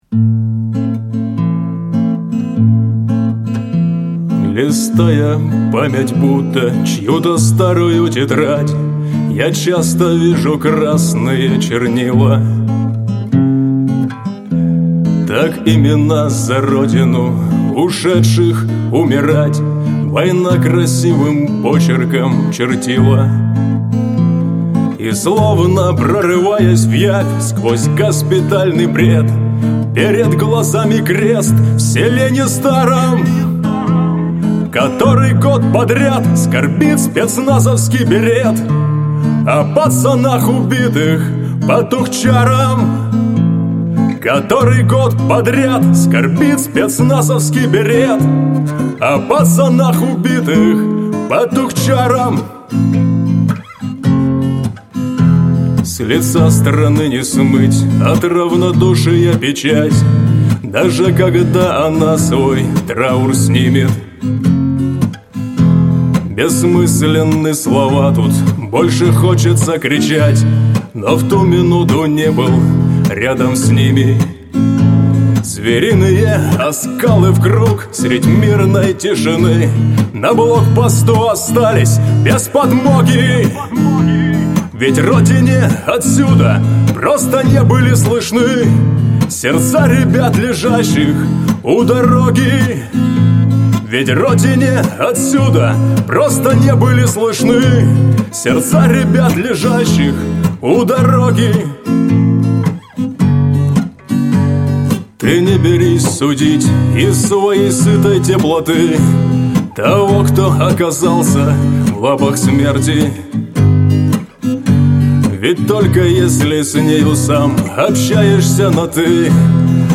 Песни под гитару
Армейские под гитару